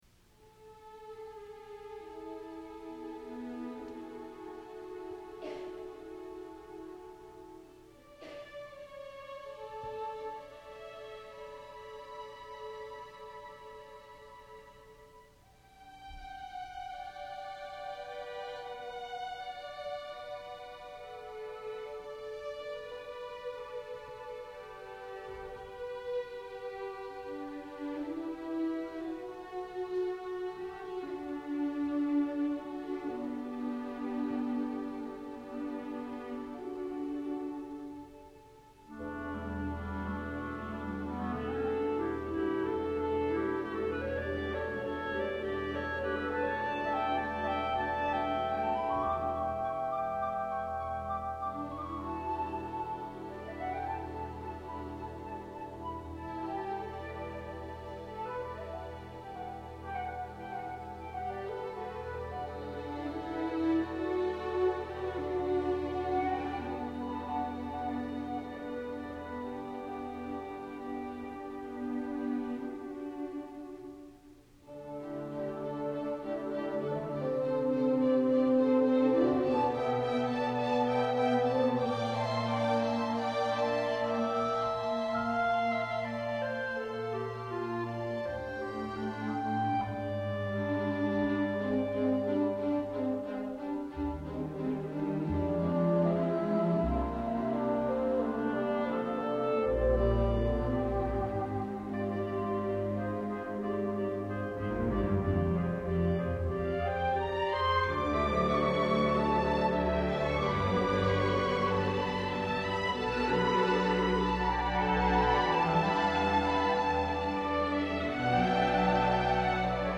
for Chamber Orchestra (1997)